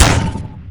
hit_scan.wav